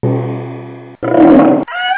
GongRoarCockShort.wav